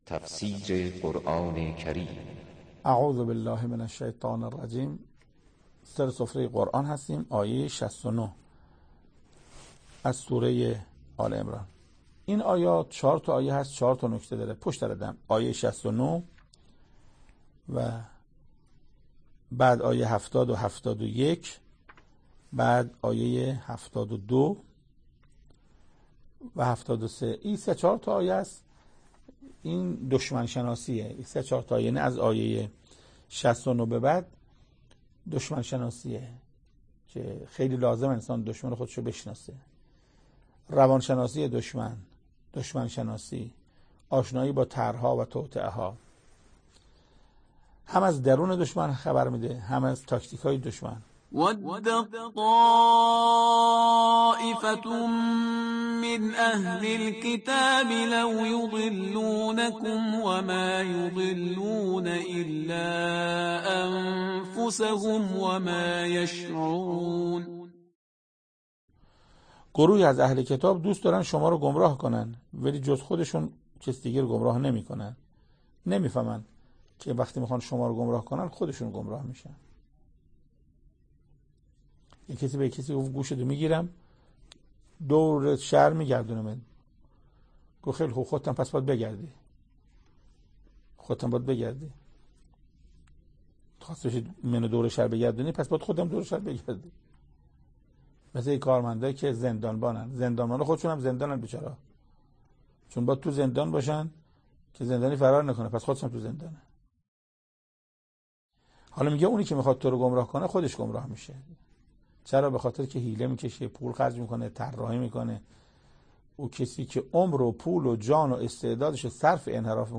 تفسیر شصت و نهمین آیه از سوره مبارکه آل عمران توسط حجت الاسلام استاد محسن قرائتی به مدت 7 دقیقه